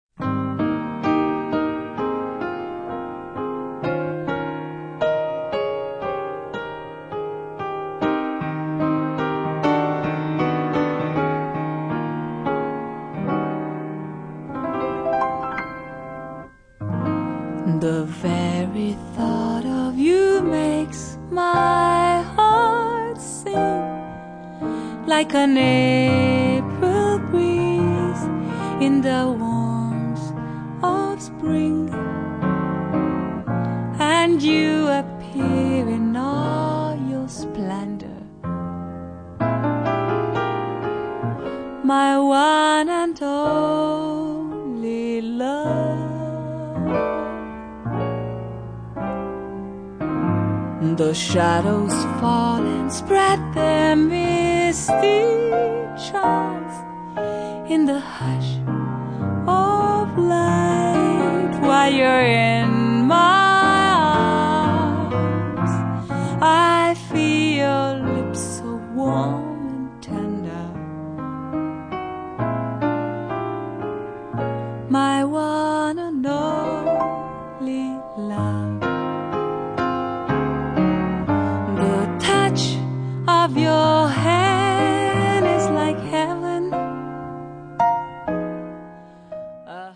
La bella e morbida voce